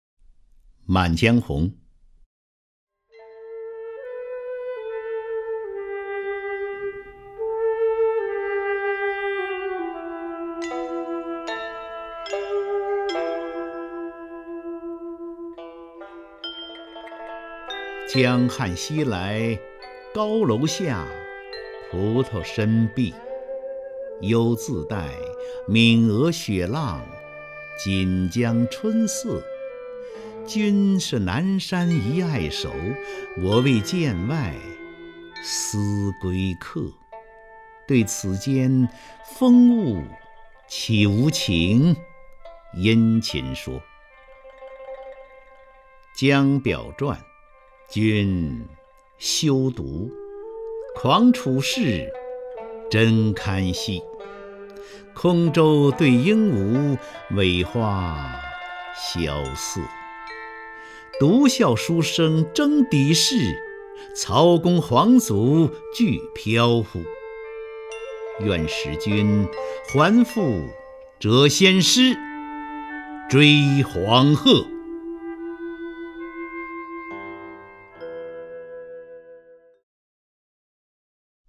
首页 视听 名家朗诵欣赏 张家声
张家声朗诵：《满江红·江汉西来》(（北宋）苏轼)